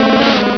pokeemerald / sound / direct_sound_samples / cries / gloom.aif
-Replaced the Gen. 1 to 3 cries with BW2 rips.
gloom.aif